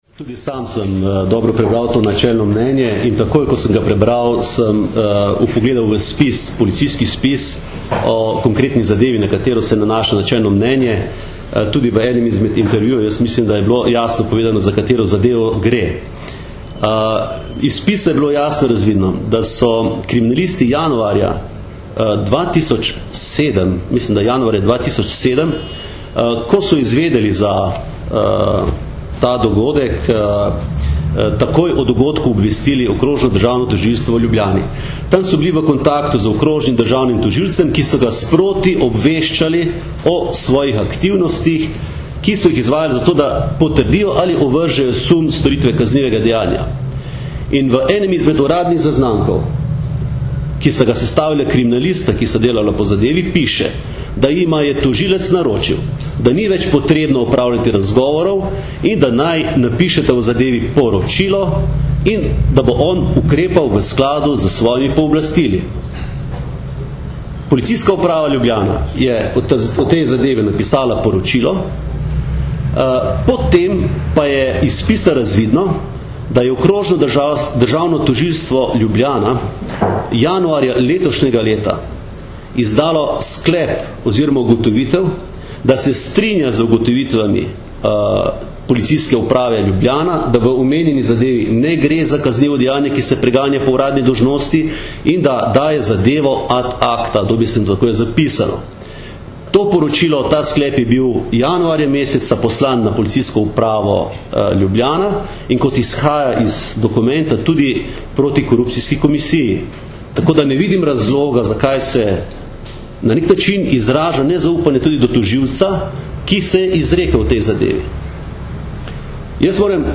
Na novinarski konferenci je mag. Jevšek odgovoril tudi na aktualna vprašanja glede zadnjih odmevnejših zadev.